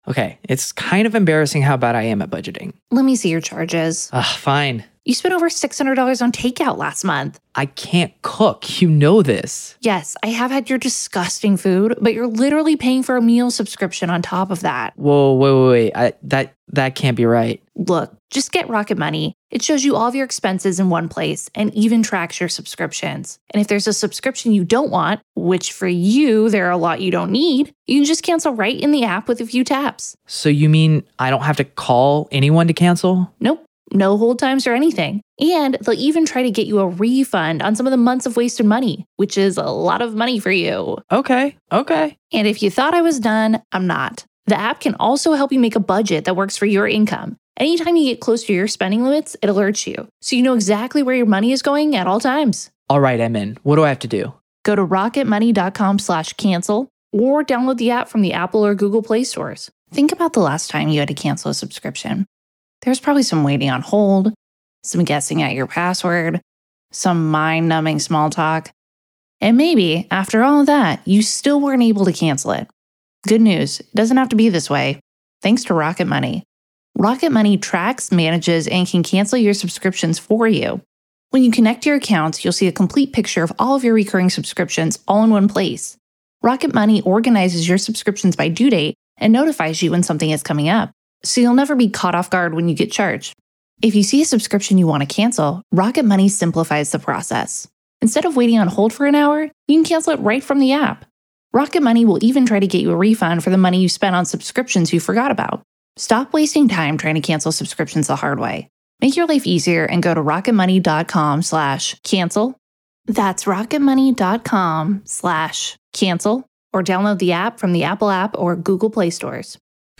The latest Spanish news headlines in English: March 31st am